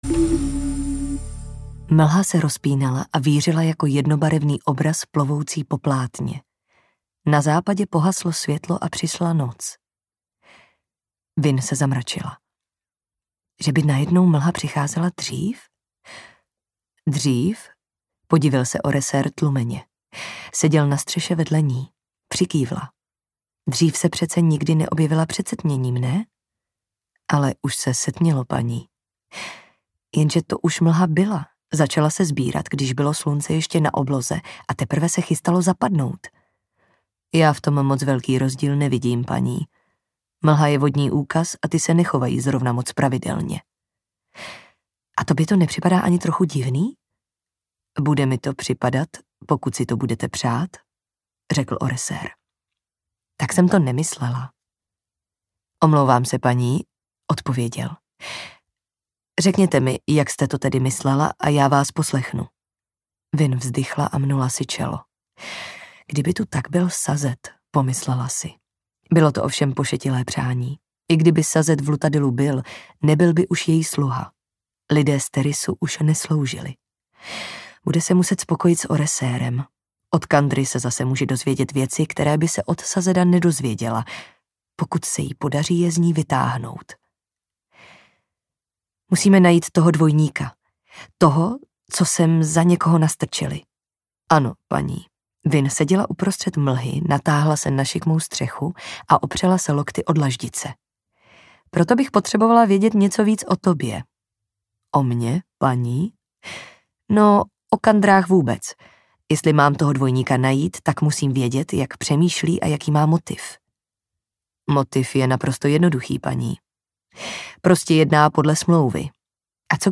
Audio knihaMistborn II. : Pramen povýšení
Ukázka z knihy